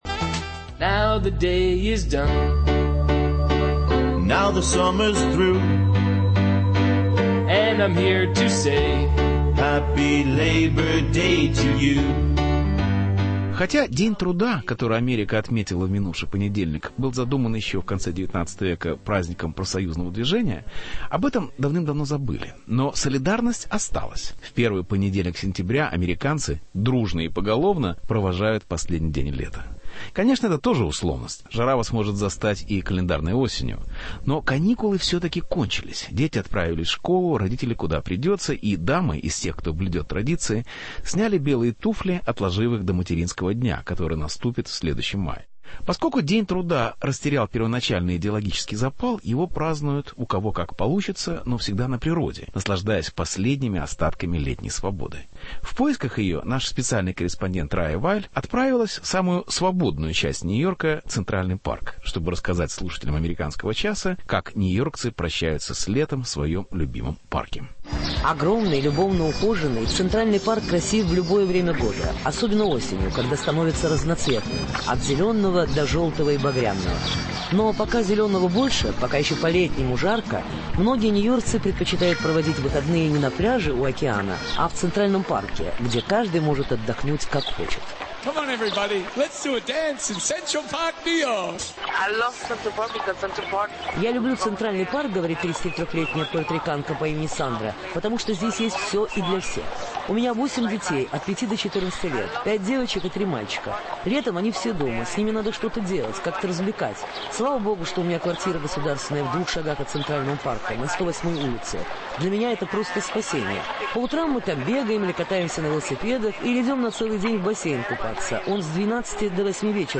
Последний день лета. Репортаж из Нью-Йорка